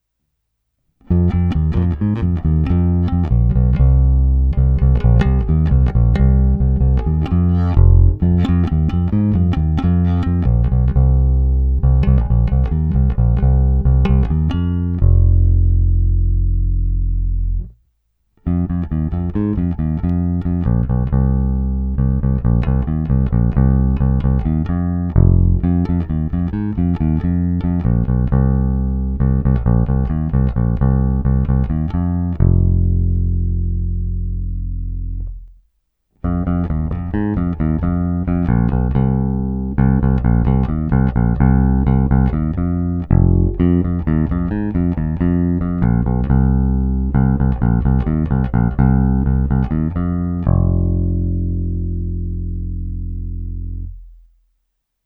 Plné basy tmelící kapelu, výrazné středy, které basu prosadí v mixu, příjemné výšky potřebné pro zkreslení, slap a vyhrávky. Není-li uvedeno jinak, následující nahrávky jsou provedeny rovnou do zvukové karty, jen normalizovány, jinak ponechány bez úprav.
Bonusová nahrávka v pořadí hra u krku, hra nad snímačem, a nakonec hra u kobylky